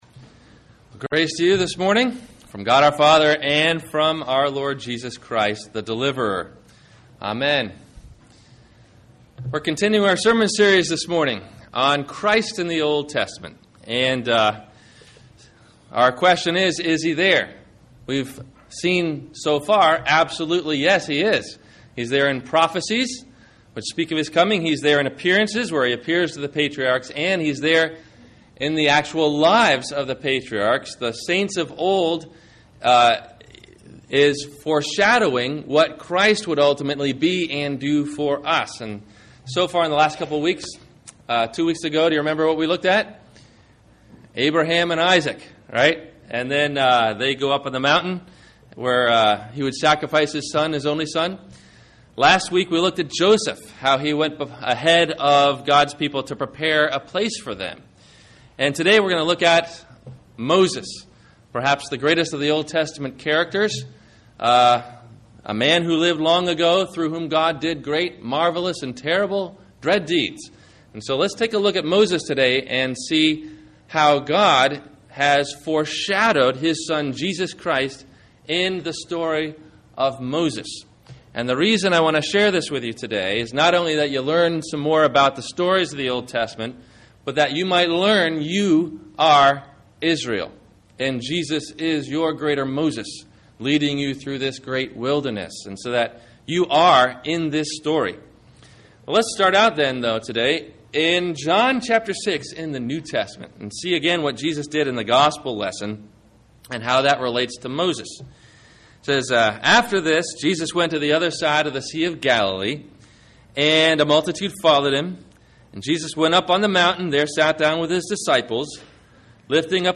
The Greater Moses – Sermon – March 15 2009
Listen for these questions and answers about “The Greater Moses”, in the 1-part MP3 Audio Sermon below.